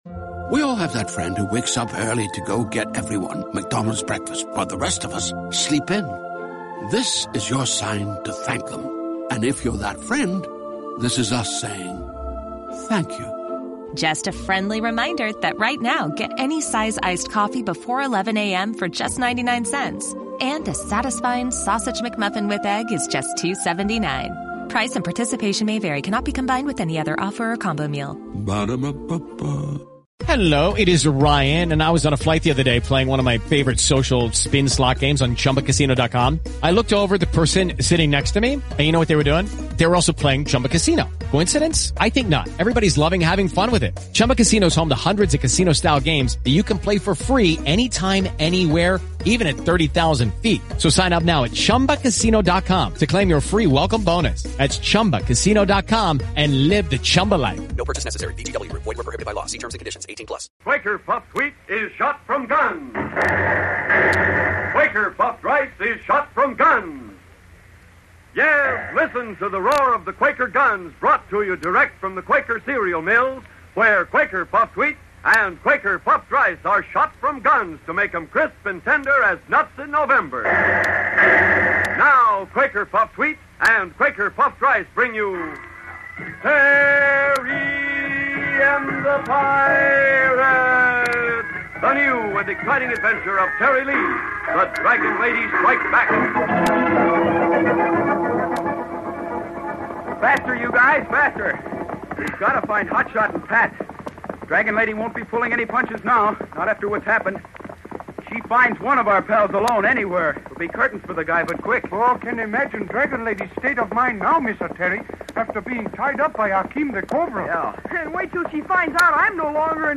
Terry and the Pirates was an American radio serial adapted from the comic strip of the same name created in 1934 by Milton Caniff. With storylines of action, high adventure and foreign intrigue, the popular radio series enthralled listeners from 1937 through 1948.